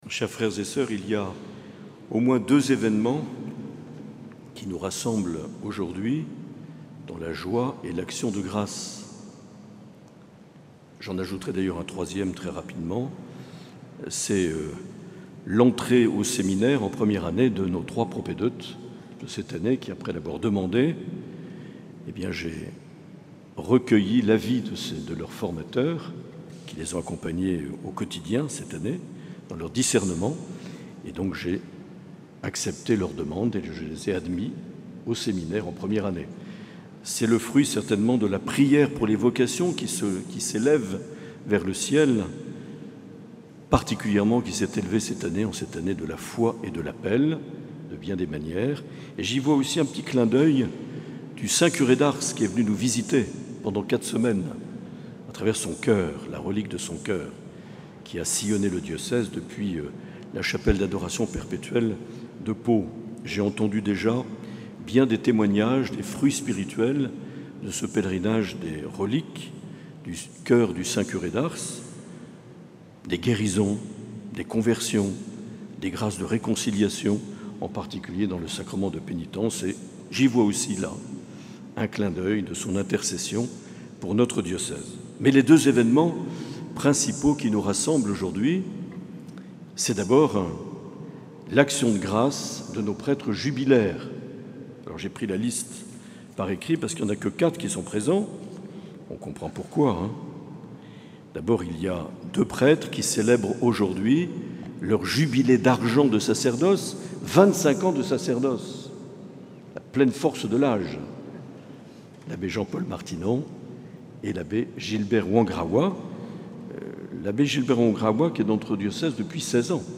Accueil \ Emissions \ Vie de l’Eglise \ Evêque \ Les Homélies \ 24 juin 2024 - Nativité de Saint Jean-Baptiste - Action de grâce pour les (...)
Une émission présentée par Monseigneur Marc Aillet